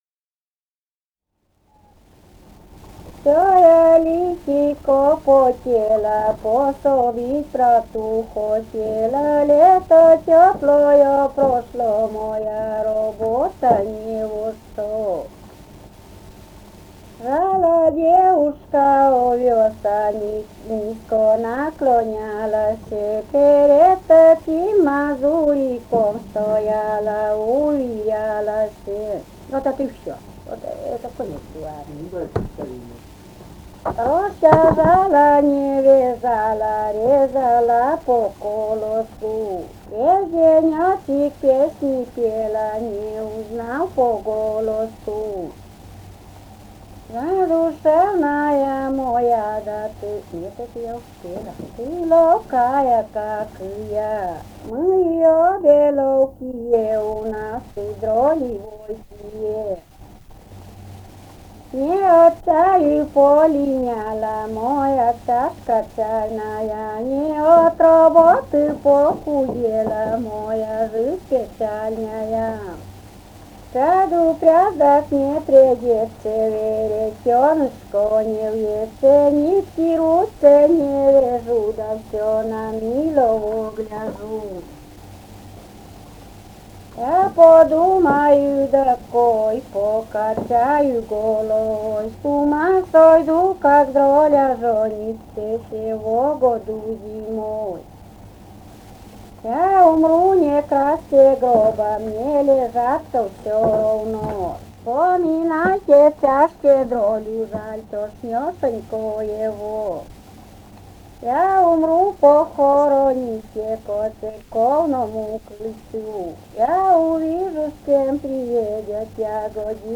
«Всё я литико потела» (частушки).